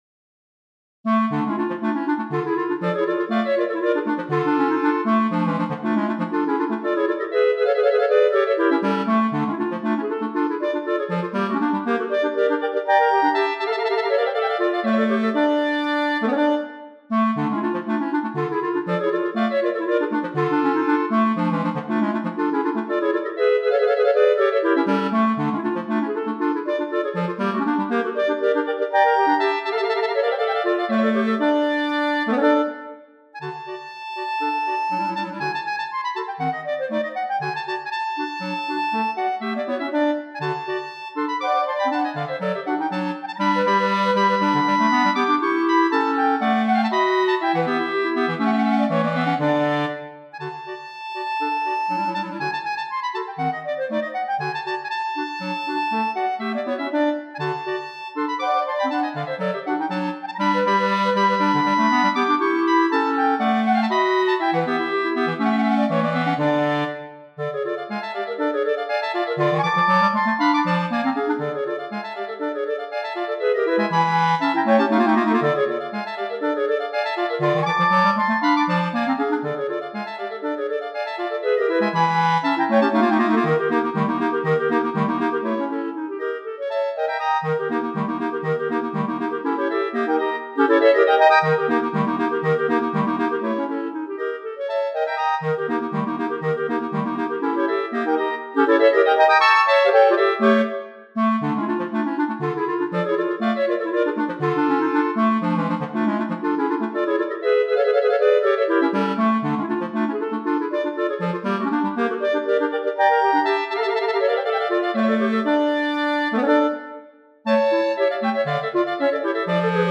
3 Clarinettes